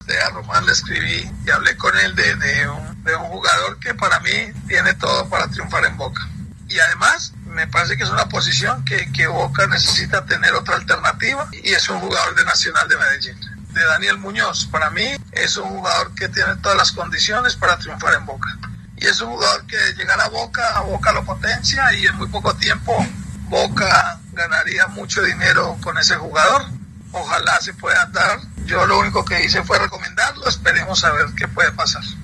(Mauricio 'Chicho' Serna en diálogo con Fox Sports)